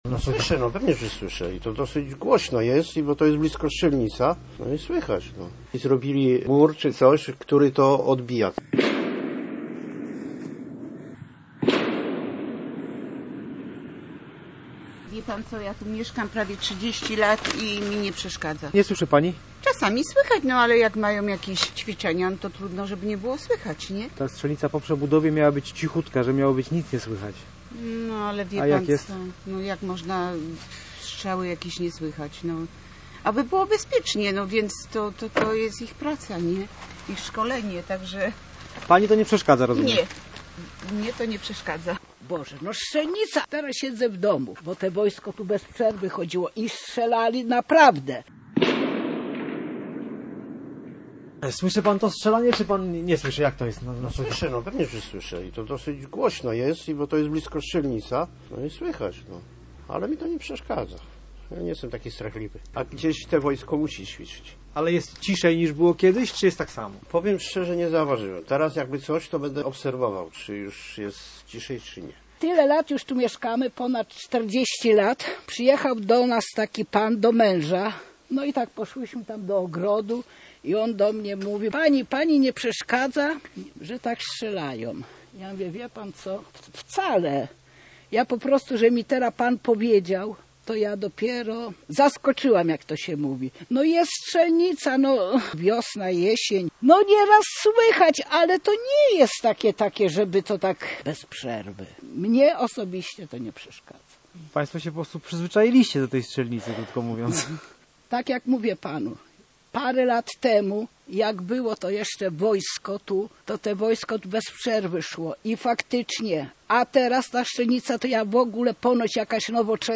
Posłuchaj materiału reportera Radia Gdańsk: